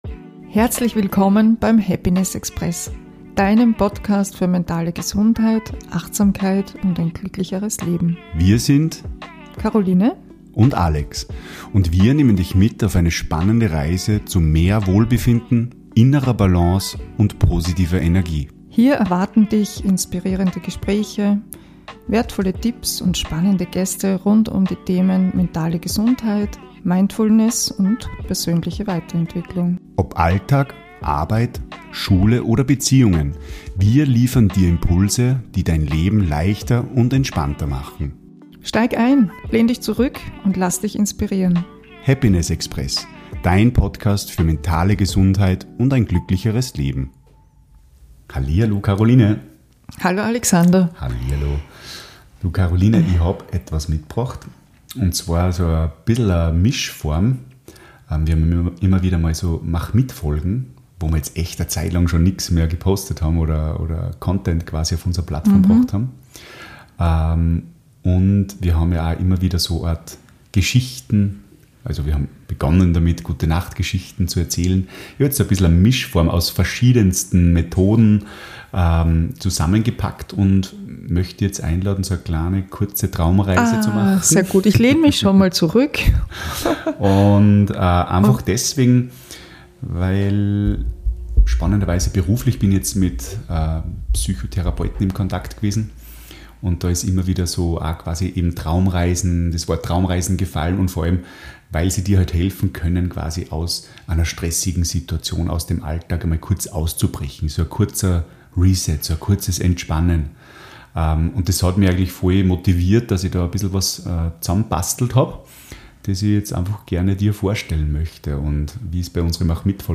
Nimm dir Zeit für dich, lausche der ruhigen Stimme, und lass dich